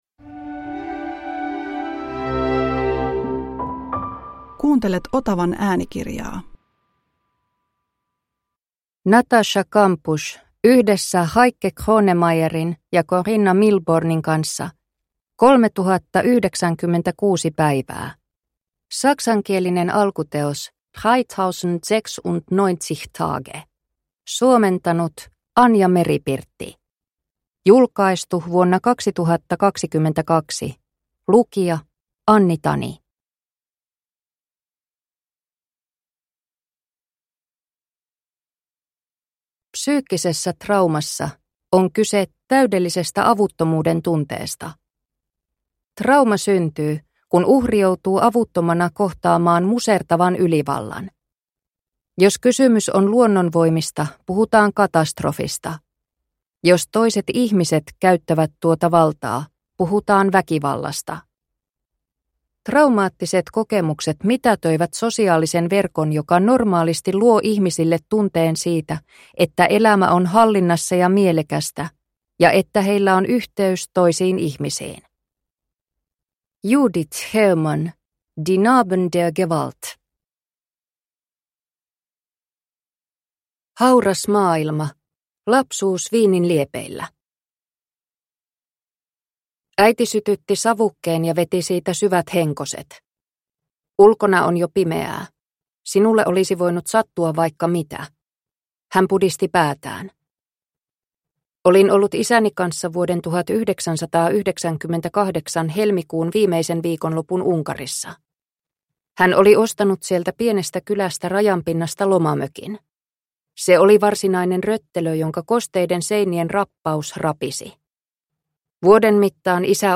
3096 päivää – Ljudbok – Laddas ner